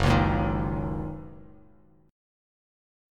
Gb6 Chord
Listen to Gb6 strummed